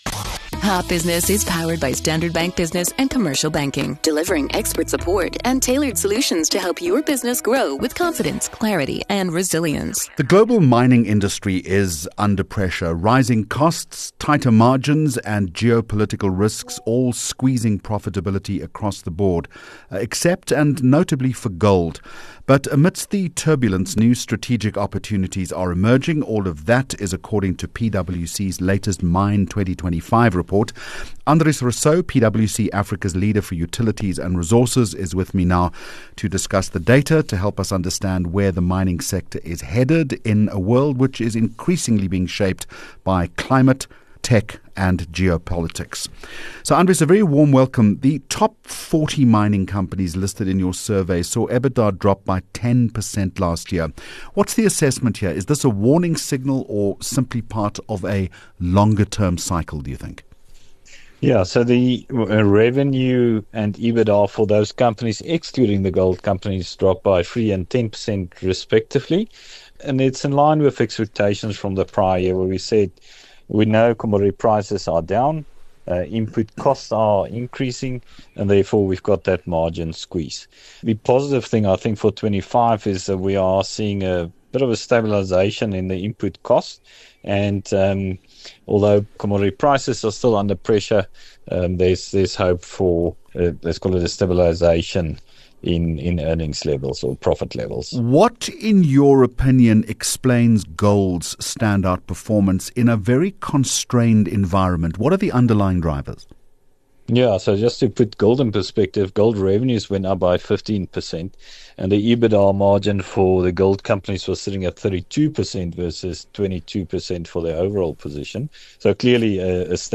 30 Jun Hot Business Interview